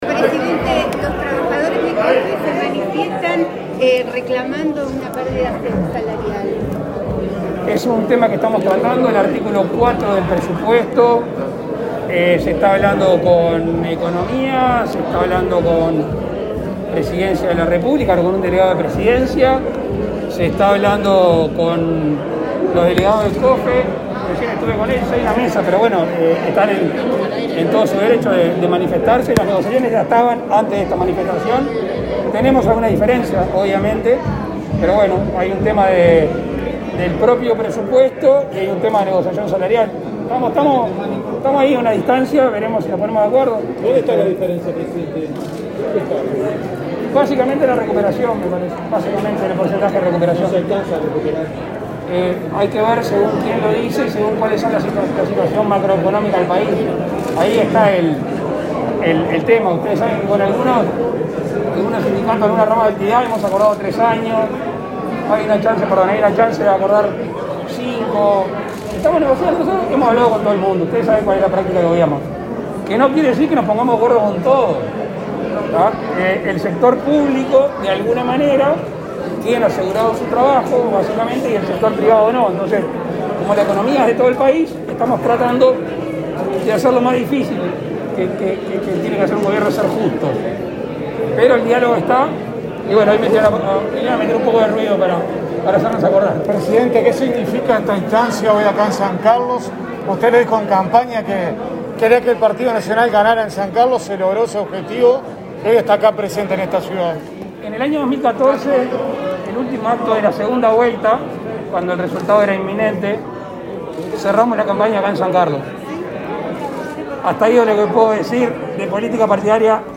“La política va de lo chico a lo grande y no al revés, por eso la presencia del Gobierno en San Carlos”, indicó el presidente de la República, Luis Lacalle Pou, al encabezar este viernes 27 el acto de asunción del alcalde de esa ciudad fernandina, Carlos Pereyra. El mandatario manifestó la voluntad del Ejecutivo de apoyar a los vecinos y consideró que se trata de un día de celebración y compromiso.